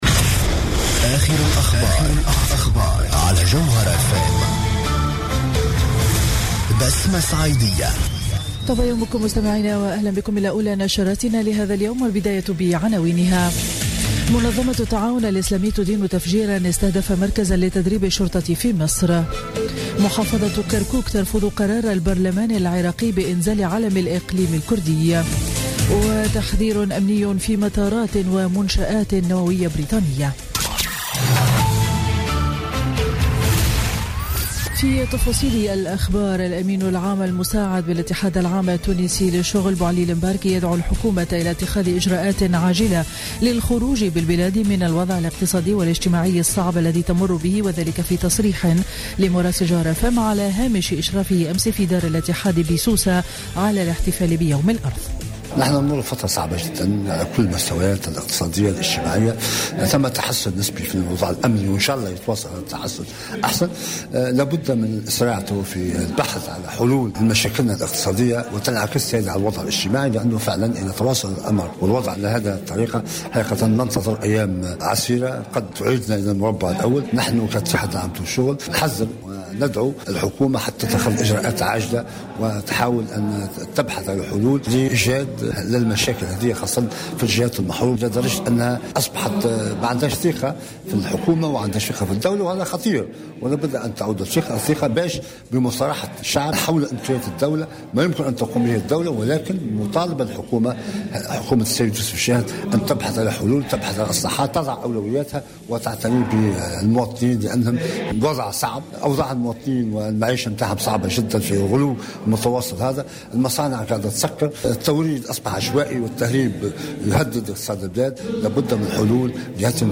نشرة أخبار السابعة صباحا ليوم الأحد 2 أفريل 2017